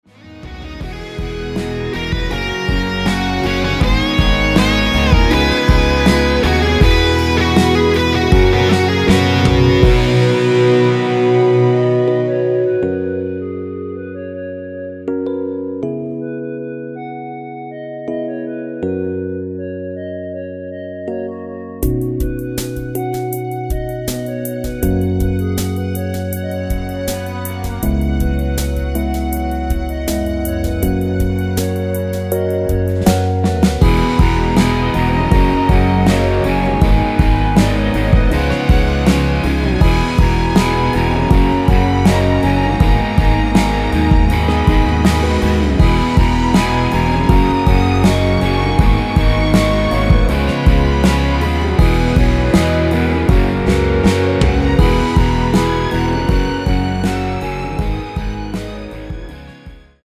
원키에서(-1)내린 멜로디 포함된 MR입니다.(미리듣기 확인)
◈ 곡명 옆 (-1)은 반음 내림, (+1)은 반음 올림 입니다.
멜로디 MR이라고 합니다.
앞부분30초, 뒷부분30초씩 편집해서 올려 드리고 있습니다.
중간에 음이 끈어지고 다시 나오는 이유는